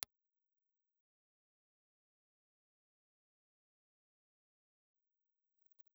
Impluse Response file of the BSR ribbon microphone with new ribbon and original transformer.
BSR_Old_Xformer.wav
Note that the sound files and sweeps provided for this model are from restored microphones that have been fitted with new magnets and ribbons.